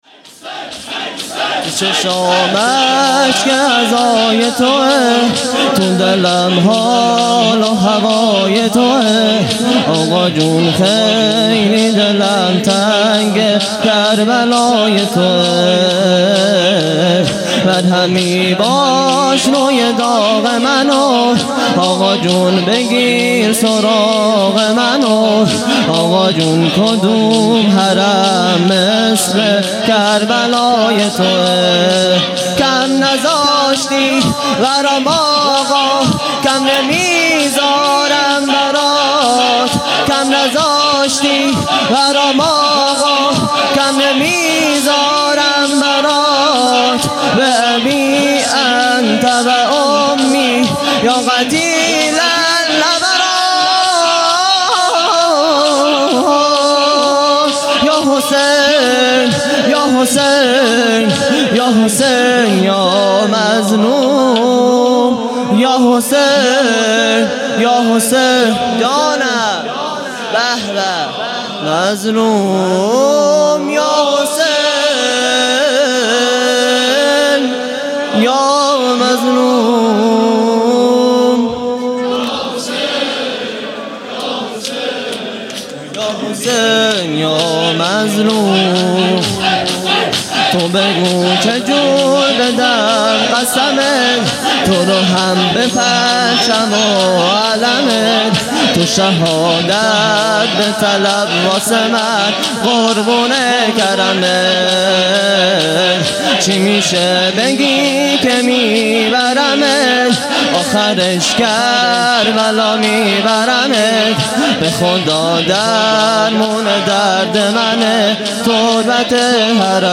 خیمه گاه - هیئت بچه های فاطمه (س) - شور | تو چشام اشک عزای توئه